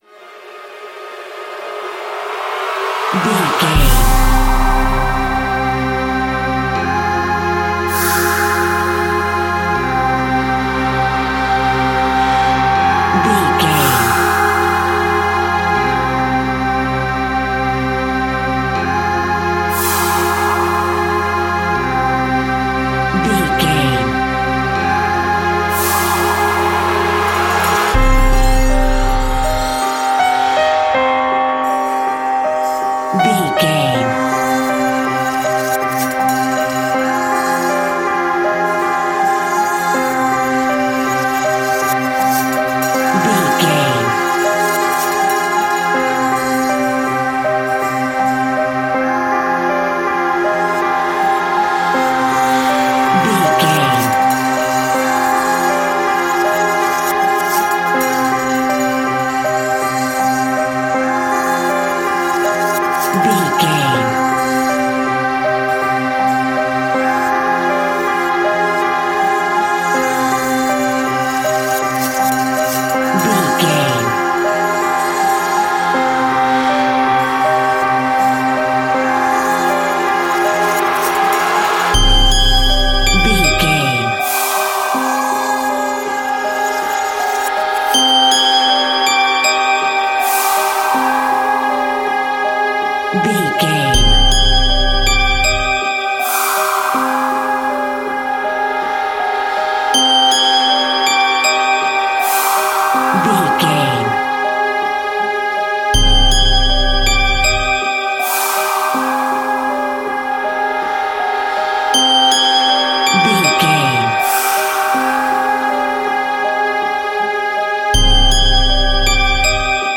Music
Aeolian/Minor
scary
tension
ominous
eerie
strings
synthesiser
piano
Horror Synths
Scary Strings